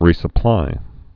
(rēsə-plī)